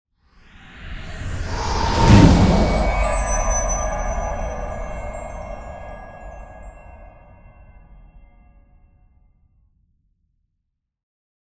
Magic Spell - Sleeping spell 1.wav